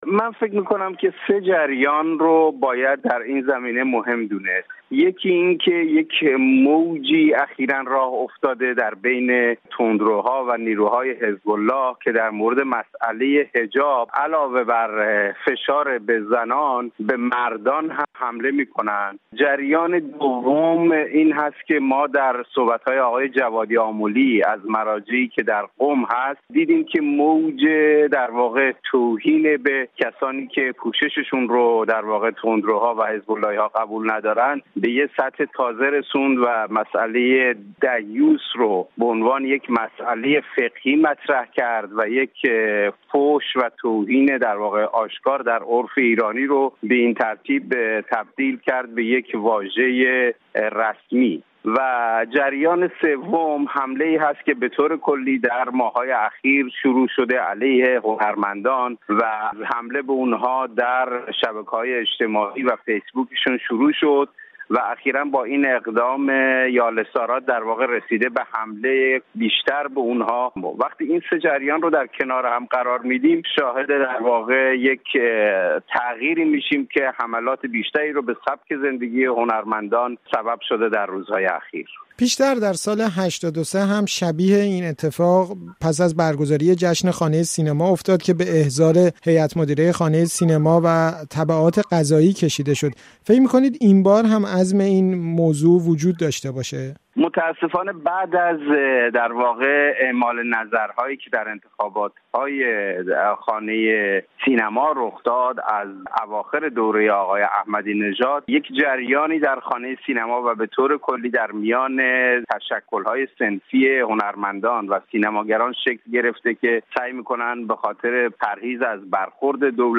گفت‌و‌گوی